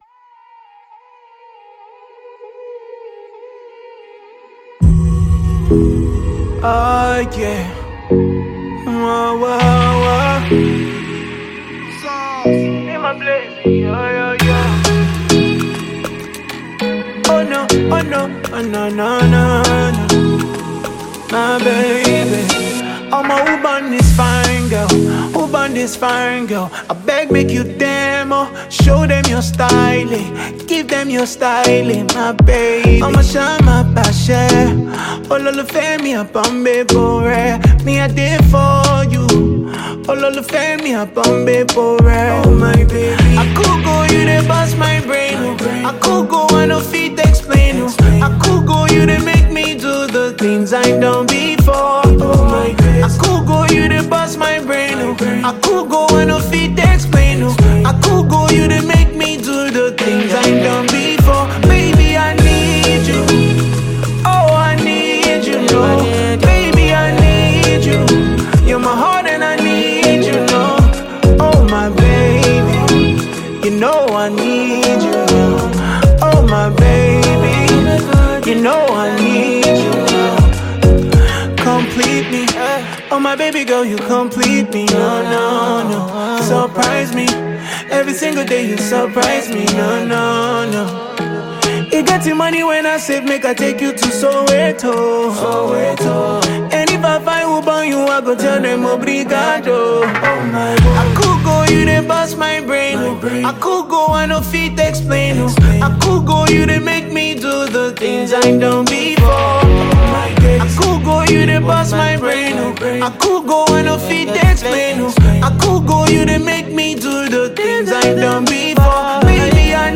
Mp3 Gospel Songs